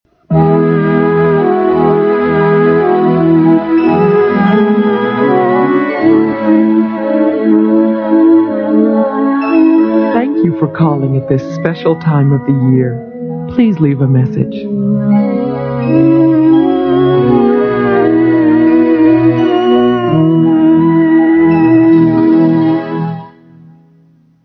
Phonies Holiday Telephone Answering Machine Messages